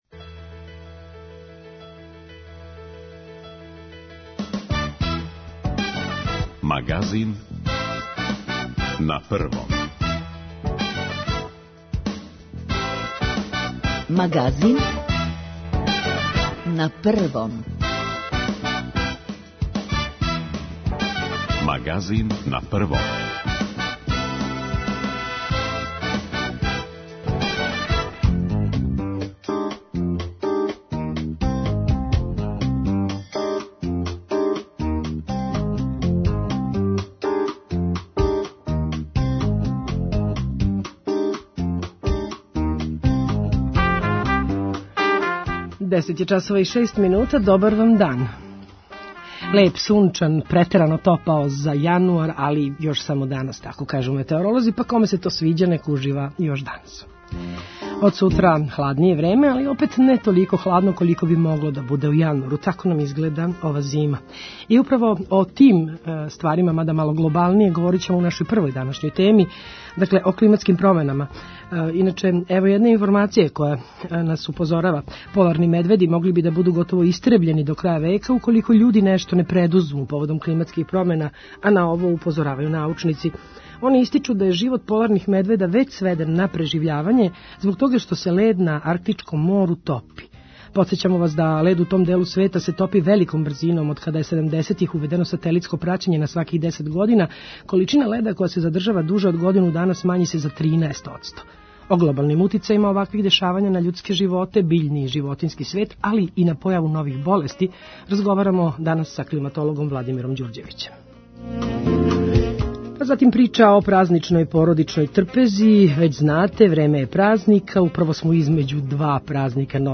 разговарамо са климатологом